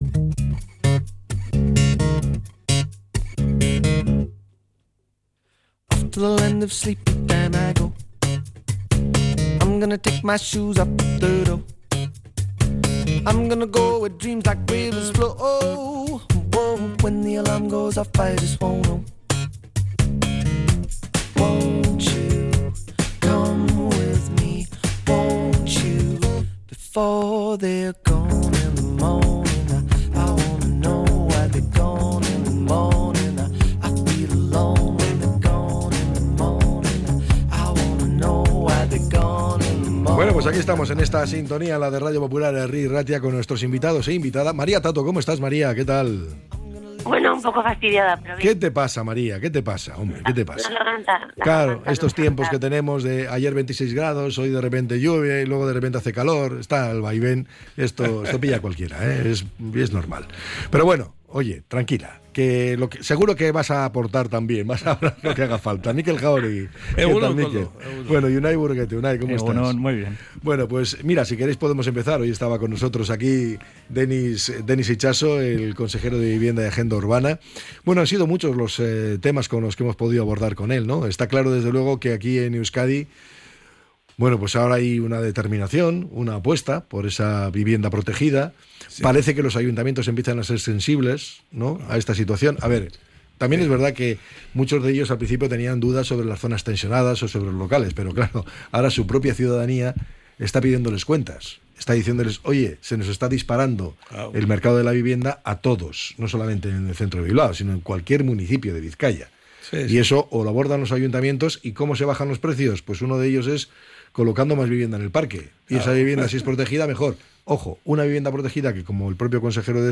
La tertulia 19-05-25.